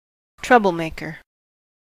troublemaker: Wikimedia Commons US English Pronunciations
En-us-troublemaker.WAV